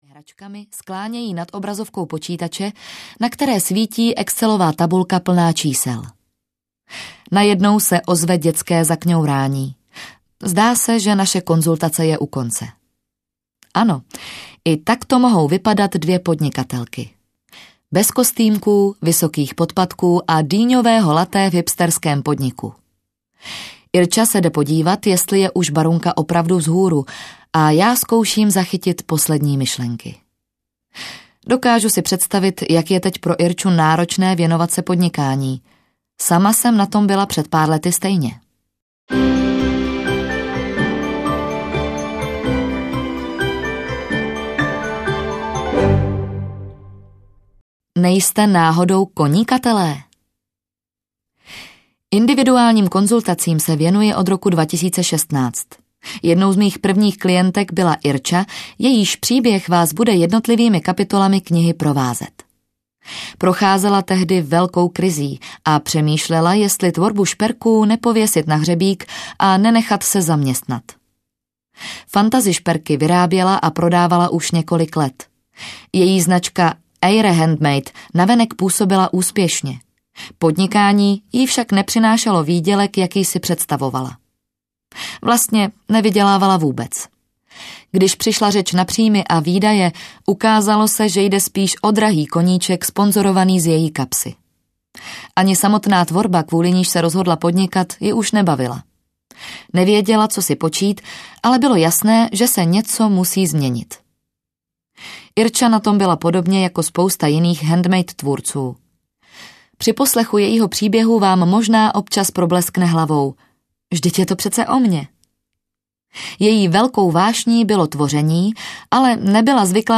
Handmade byznys audiokniha
Ukázka z knihy